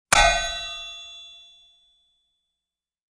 Descarga de Sonidos mp3 Gratis: metal 5.